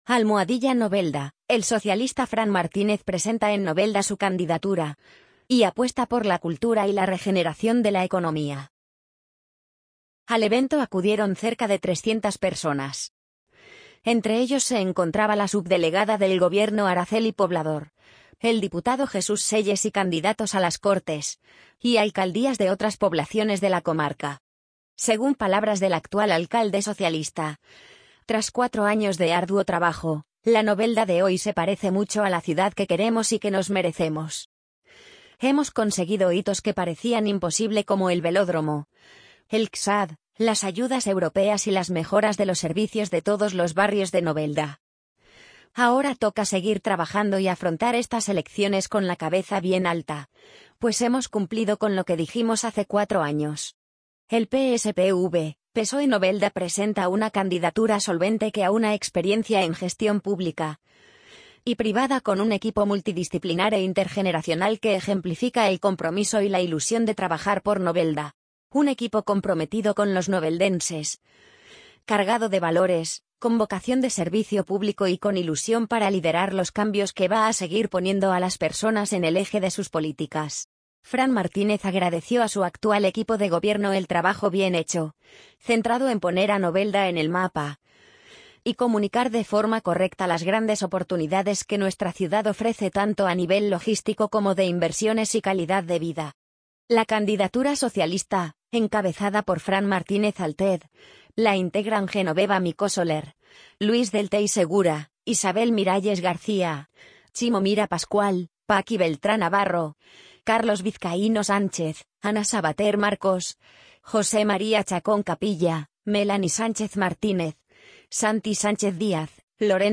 amazon_polly_65937.mp3